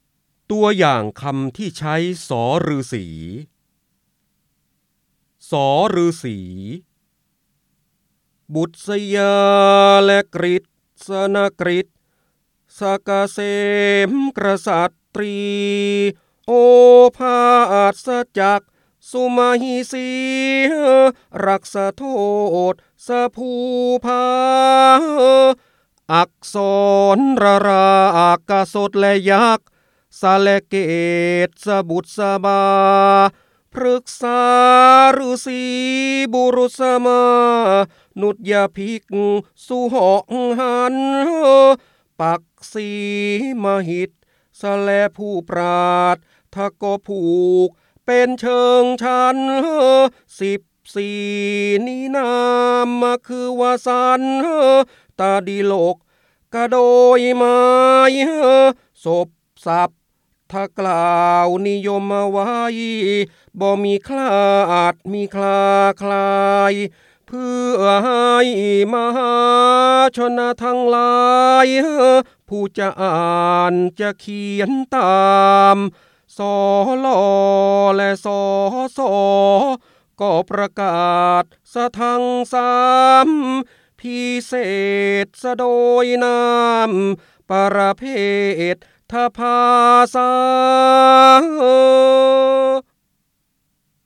เสียงบรรยายจากหนังสือ จินดามณี (พระโหราธิบดี) ตัวอย่างคำที่ใช้ ษ
คำสำคัญ : จินดามณี, พระโหราธิบดี, ร้อยแก้ว, ร้อยกรอง, พระเจ้าบรมโกศ, การอ่านออกเสียง